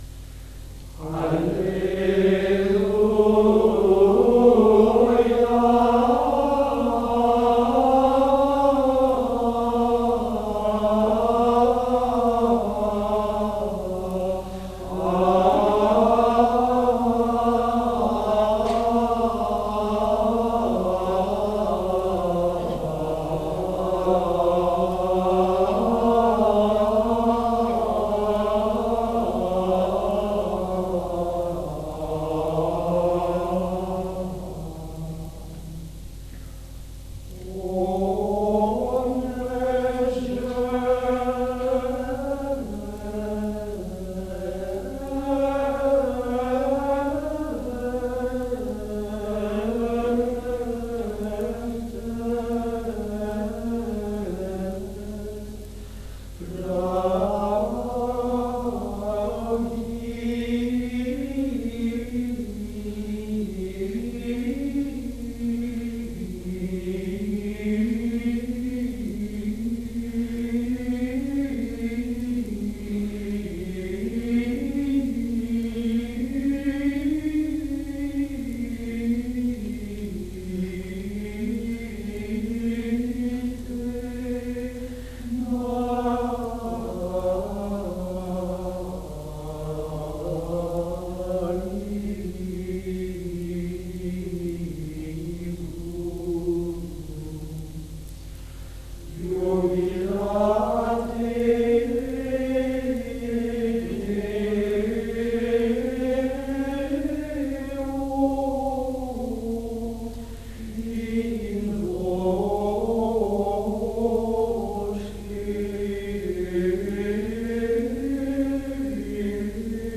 Fontevraud, juillet 1991.
La joie aussi est moins vive, moins éclatante, moins extérieure.
Tout change brusquement, au début de la seconde phrase.
Sur exultationis la joie pure, peu à peu, domine à nouveau.